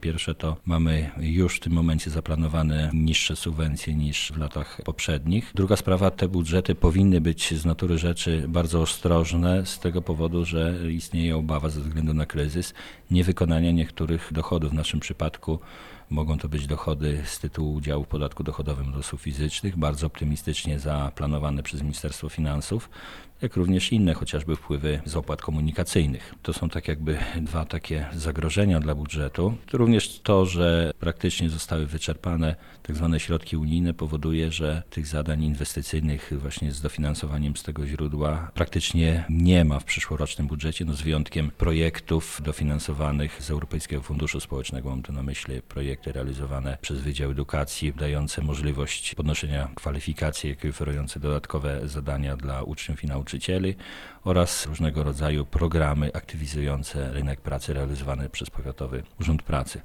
Starosta lubelski przyznaje, że przyszłoroczny budżet jest trudny i na pewno skromniejszy od tego, który był uchwalany rok temu.